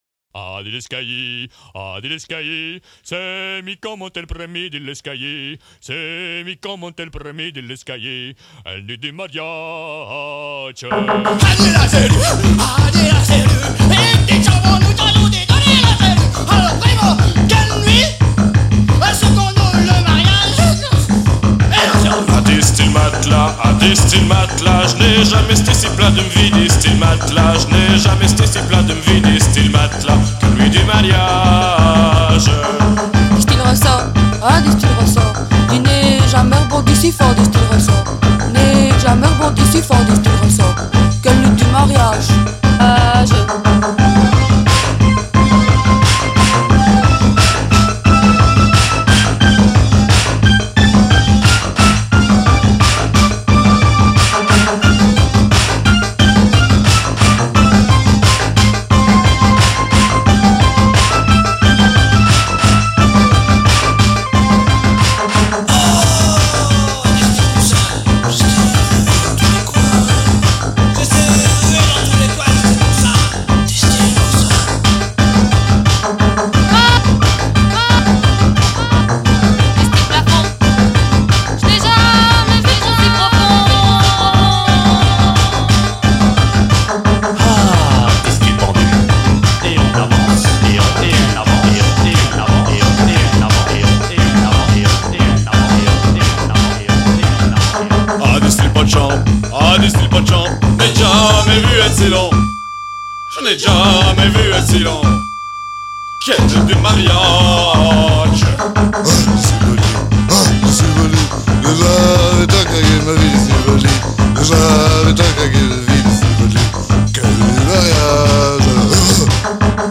plusieurs chanteurs